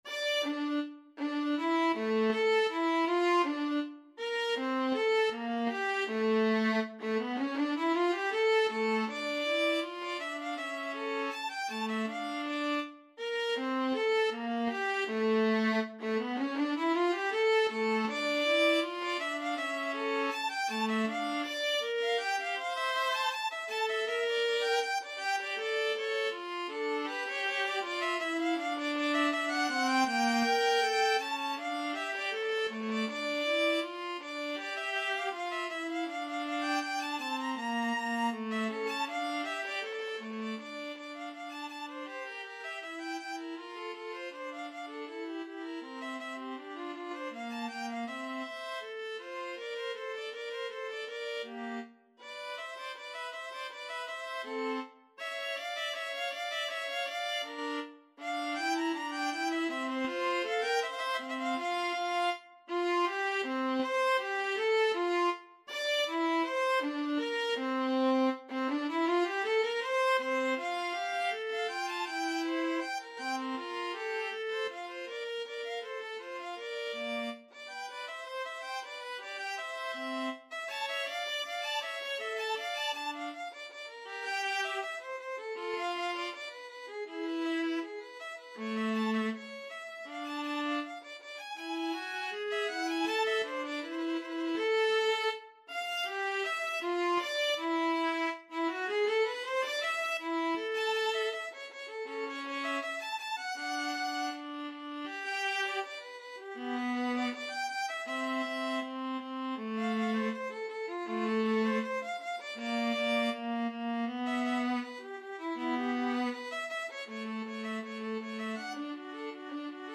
4/4 (View more 4/4 Music)
Classical (View more Classical Violin-Viola Duet Music)